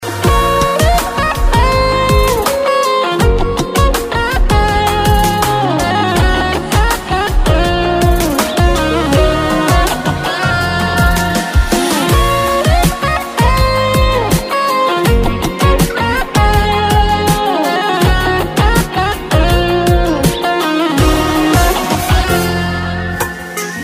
رینگتون احساسی(بی کلام)